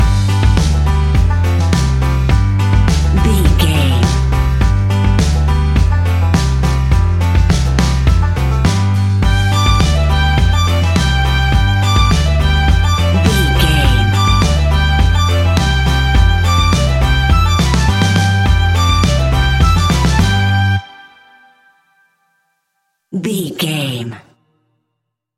Uplifting
Ionian/Major
earthy
acoustic guitar
mandolin
ukulele
lapsteel
drums
double bass
accordion